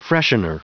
Prononciation du mot freshener en anglais (fichier audio)
Prononciation du mot : freshener